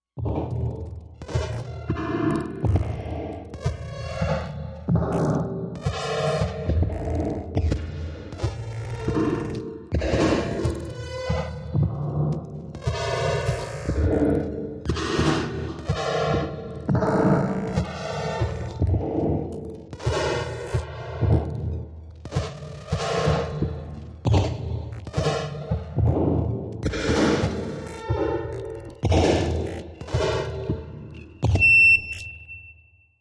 Fix Me, Machine, Broken, Electrical. Multi Robotic, Suction